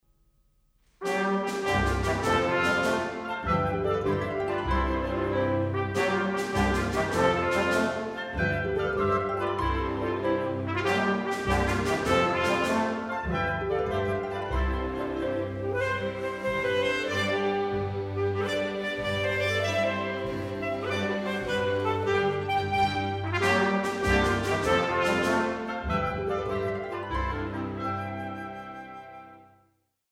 Orchesterwerke verfemter Komponisten